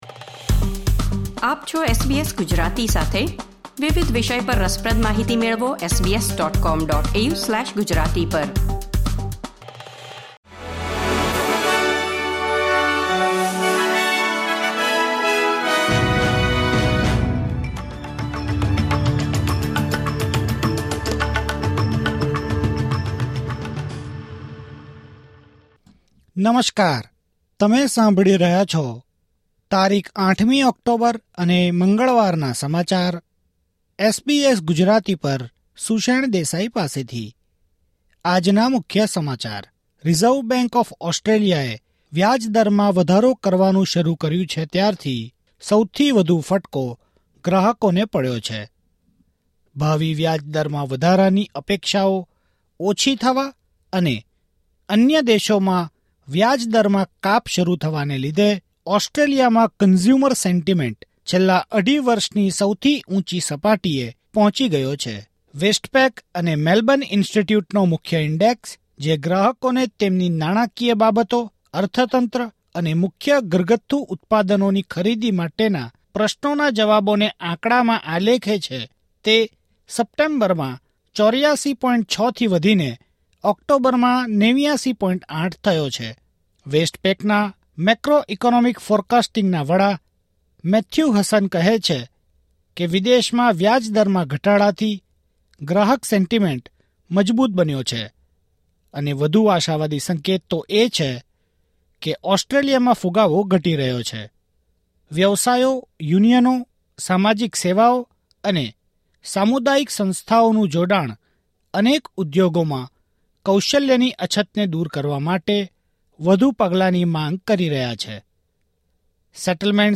૮ ઓક્ટોબર ૨୦૨૪ના મુખ્ય સમાચાર